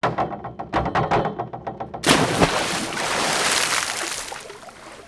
pooljump.wav